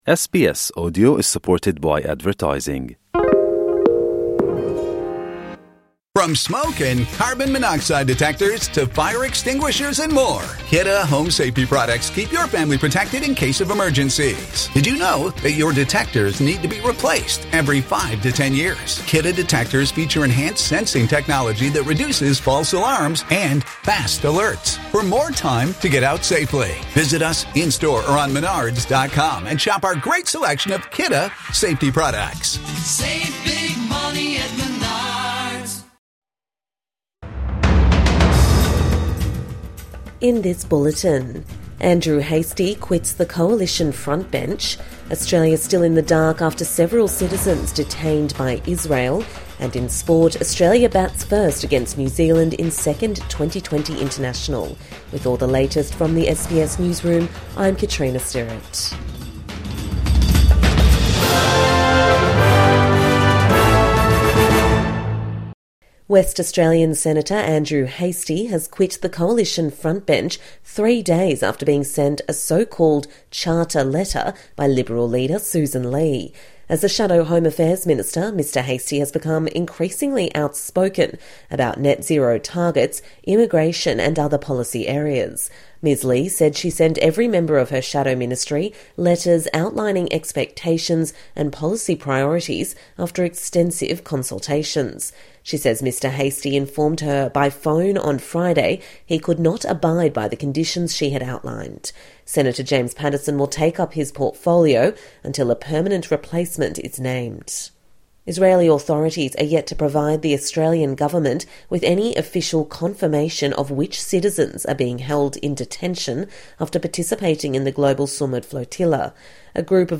Andrew Hastie quits Liberal frontbench | Morning News Bulletin 4 October 2025